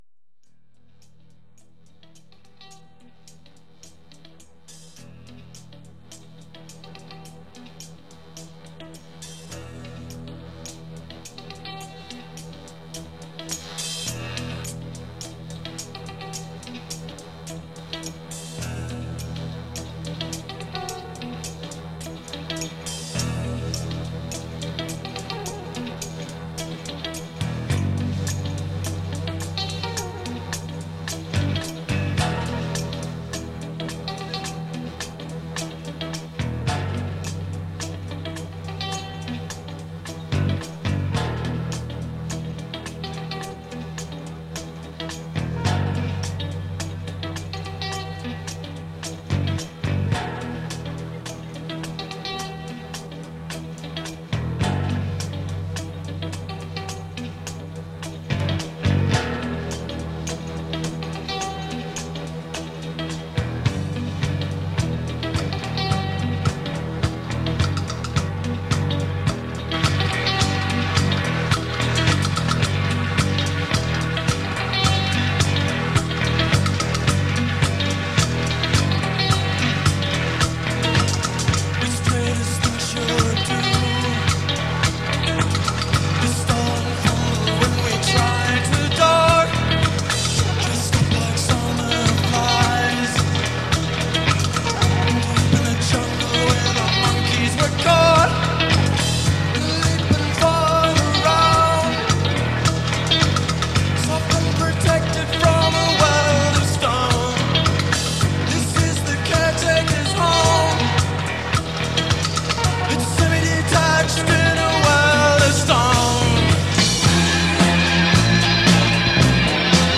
Recorded live at The Jump Club, Melbourne
Live at The Jump Club, Melbourne
Band soundboard